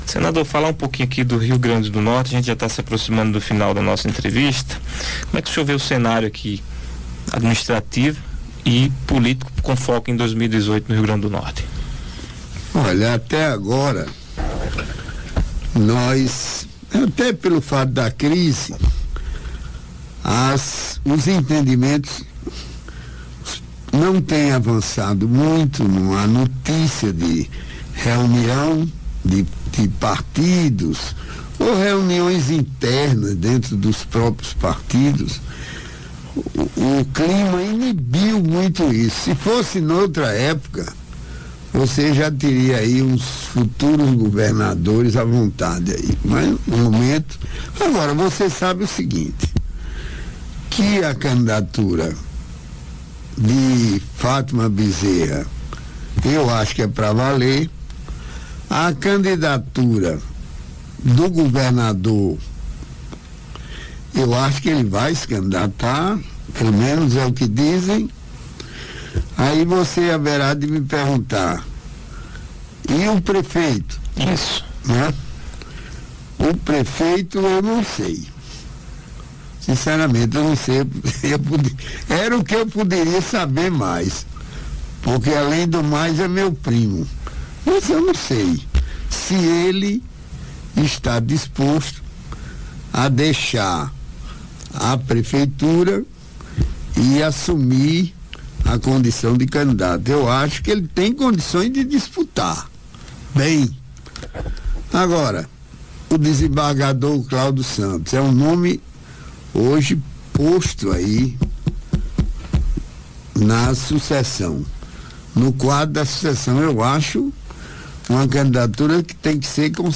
Em entrevista, Garibaldi faz elogios ao nome do desembargador Cláudio Santos
Ouça oque disse o senador: